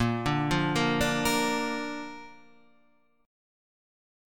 A# chord